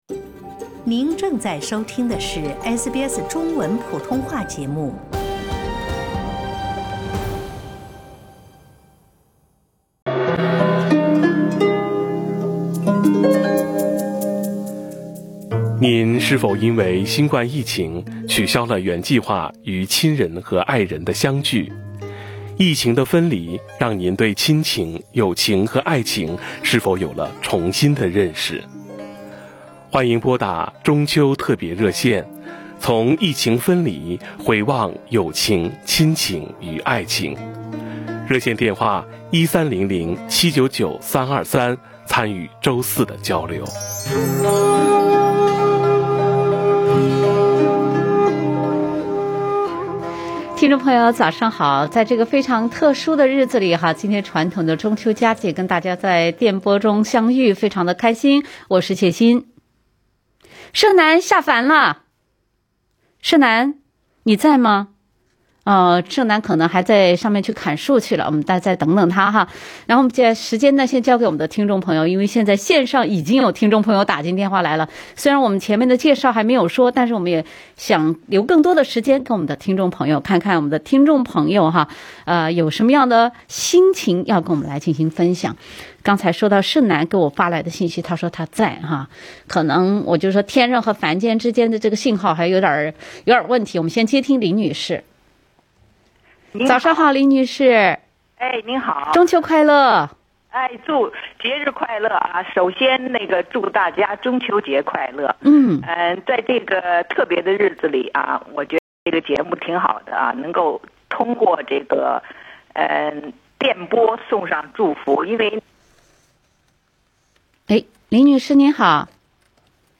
不能相见的我们如何维系、联系感情，倾诉彼此的思念和关心？（点击图片，收听直播热线）